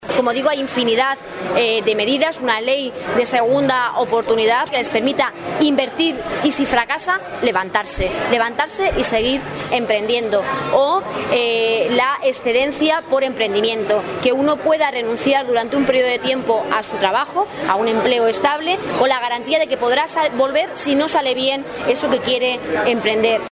Durante su visita al mercado de abasto de Ciudad Real
Cortes de audio de la rueda de prensa